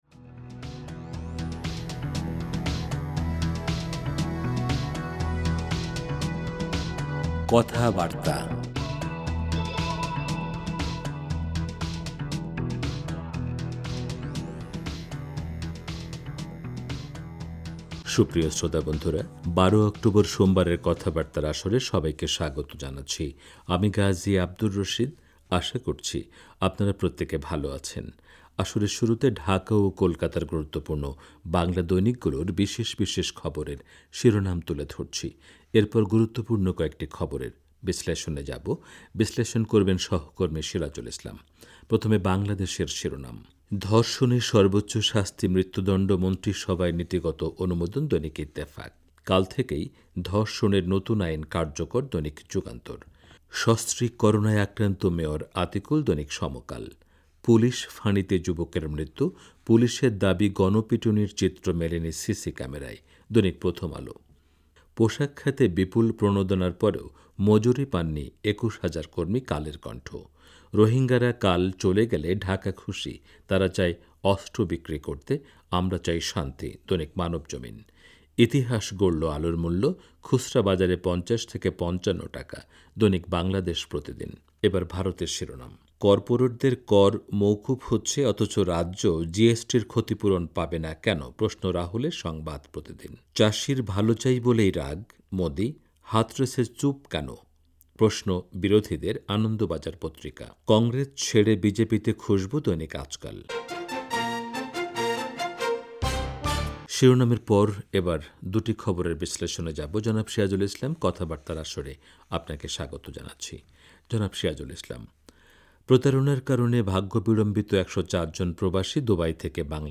আসরের শুরুতে ঢাকা ও কোলকাতার গুরুত্বপূর্ণ বাংলা দৈনিকগুলোর বিশেষ বিশেষ খবরের শিরোনাম তুলে ধরছি। এরপর গুরুত্বপূর্ণ কয়েকটি খবরের বিশ্লেষণে যাবো।